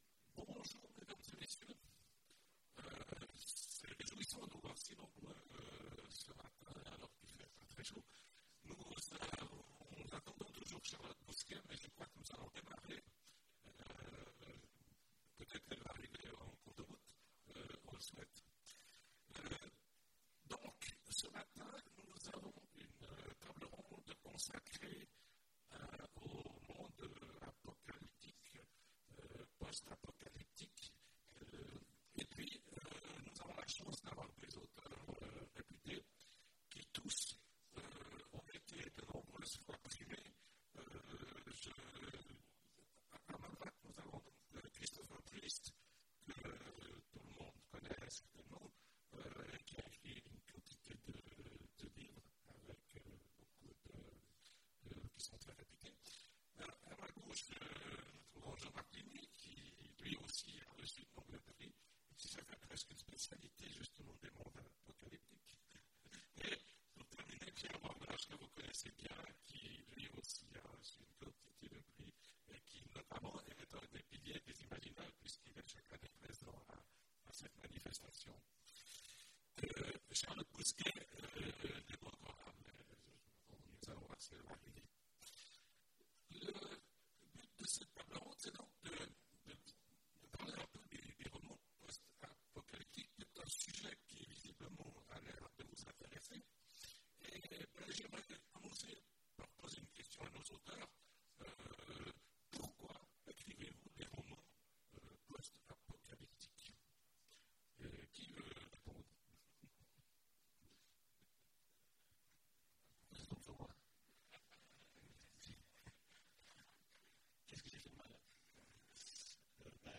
Imaginales 2017 : Conférence Quand les auteurs bâtissent… Des mondes post-apocalyptiques !